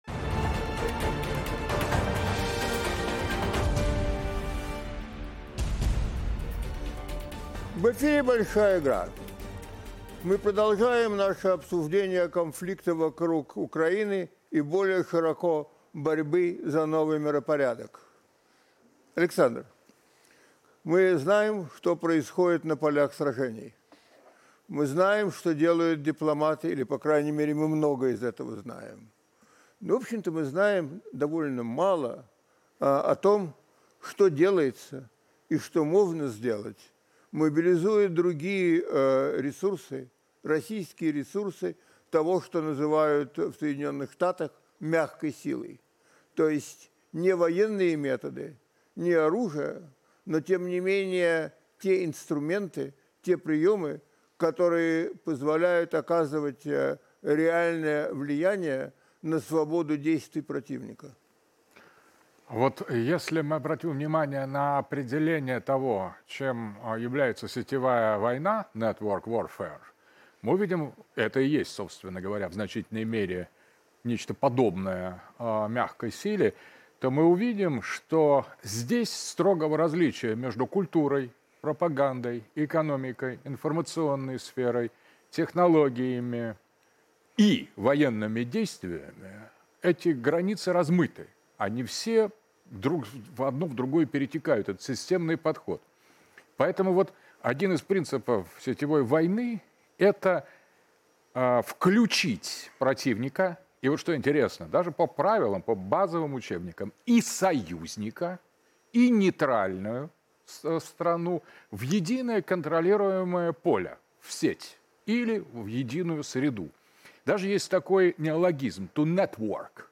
Александр Дугин в Большой Игре (23.05.2023) В студии подводят итоги дня. Актуальная информация о ходе военной спецоперации России на Украине. Эксперты обсуждают в студии процессы изменения в сознании российской элиты в связи с СВО, процессы реформирования российской армии на постсоветском пространстве, а также информационные атаки, с которыми столкнулось российское общество.